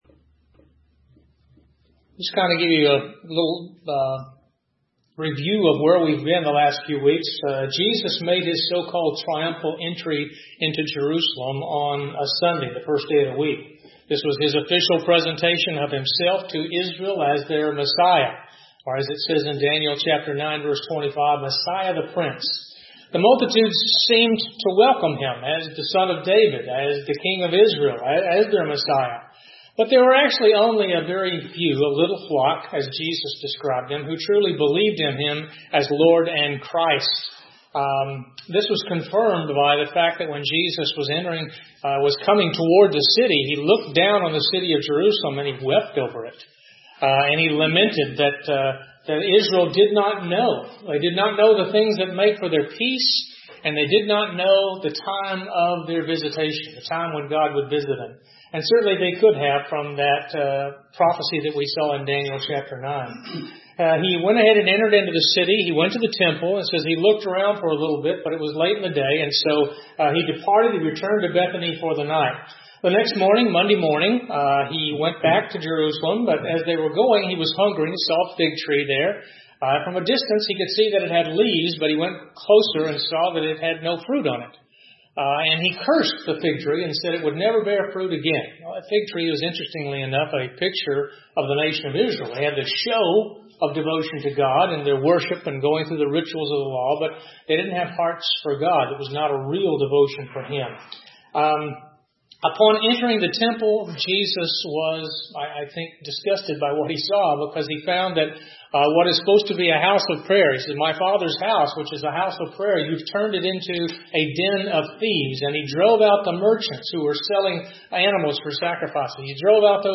Listen to Bible studies from our Adult Sunday School Class (all Bible Study Sermons are in MP3 format). These studies often cover in-depth series of Bible studies, usually doctrinal issues or studies of individual books of the Bible, all from a dispensational perspective.